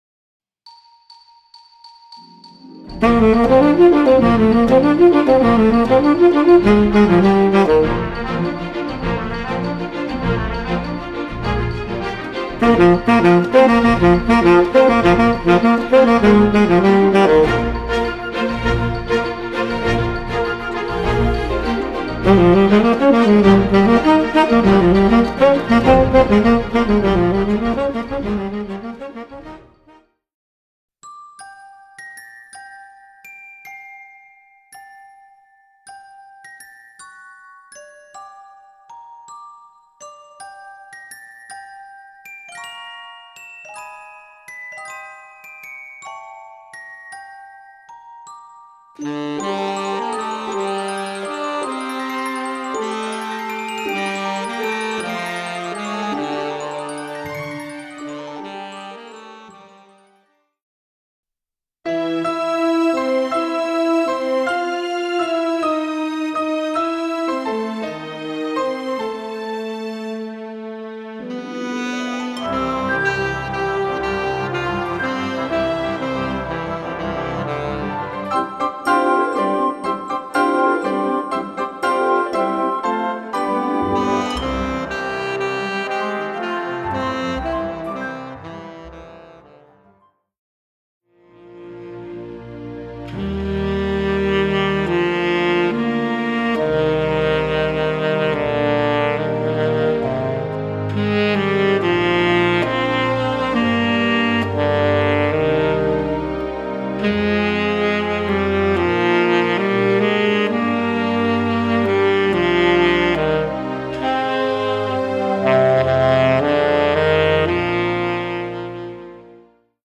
Voicing: Tenor Saxophone w/ Audio